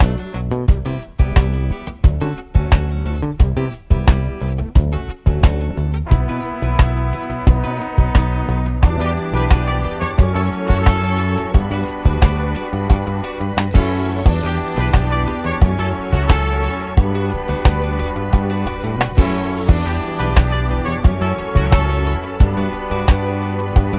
no Backing Vocals Disco 4:56 Buy £1.50